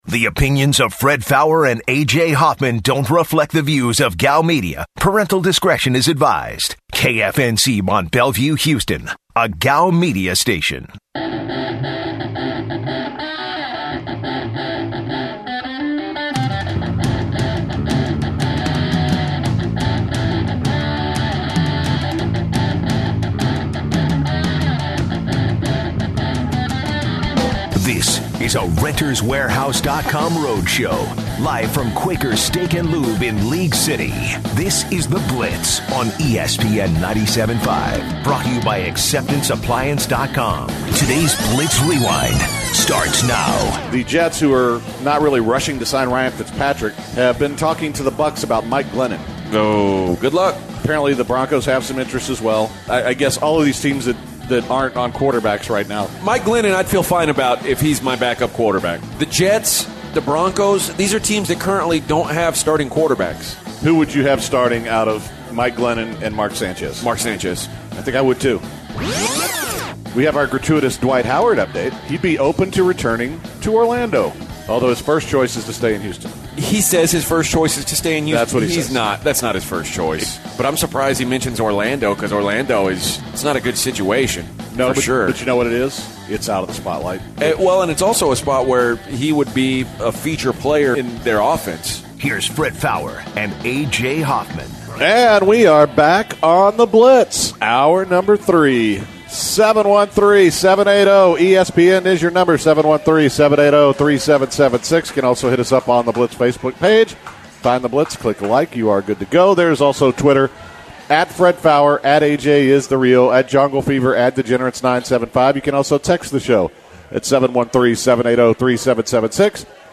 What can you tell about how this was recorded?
live from Quaker Steak and Lube